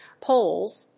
/polz(米国英語), pəʊlz(英国英語)/